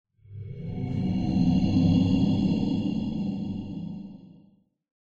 ambient / cave
update audio to prevent artifacts
cave8_fixed.ogg